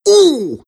Worms speechbanks
Ooff2.wav